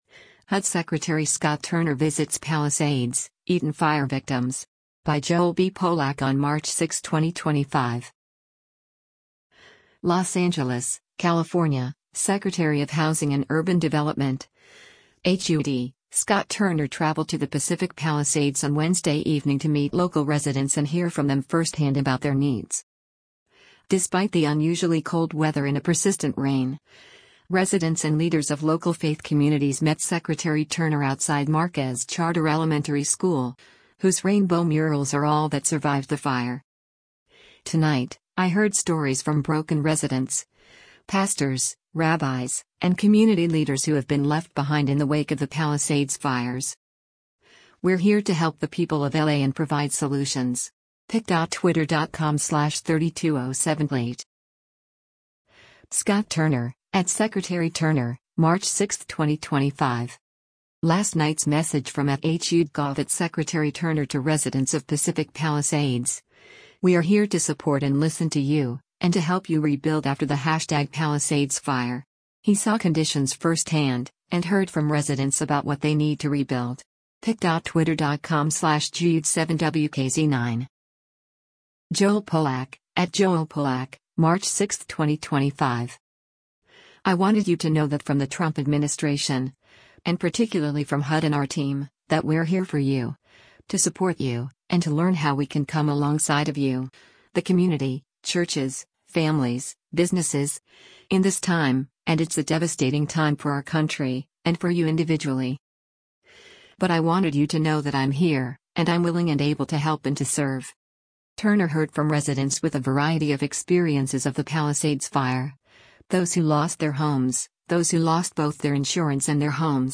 Despite the unusually cold weather and a persistent rain, residents and leaders of local faith communities met Secretary Turner outside Marquez Charter Elementary School, whose rainbow murals are all that survived the fire.